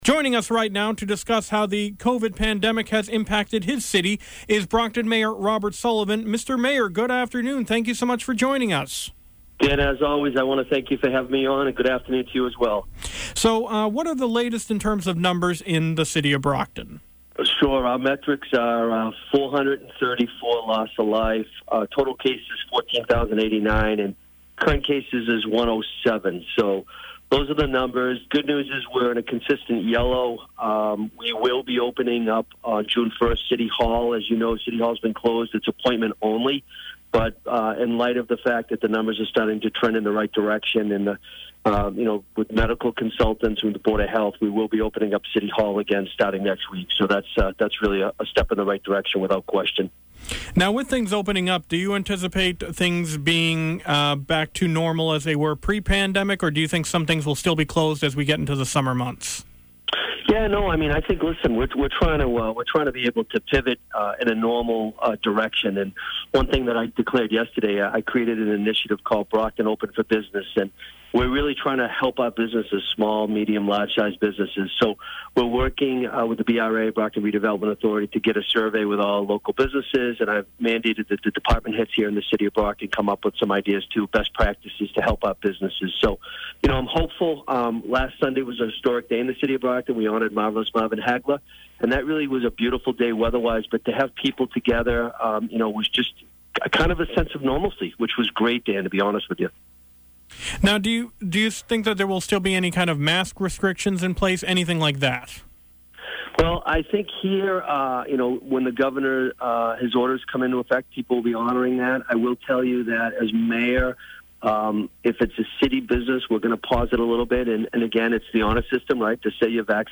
— WATD 95.9 News & Talk Radio, South Shore Massachusetts